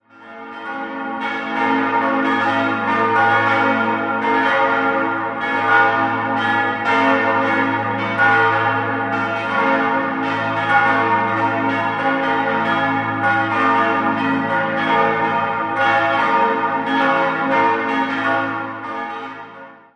Die Pfarrkirche St. Stefan wurden in den Jahren 1902 bis 1904 nach den Plänen Albert Rimlis im neubarocken Stil erbaut. 5-stimmiges Geläute: b°-des'-f'-as'-b' Die Glocken 2 bis 5 wurden 1903, die große 1932 von der Gießerei Rüetschi in Aarau gegossen.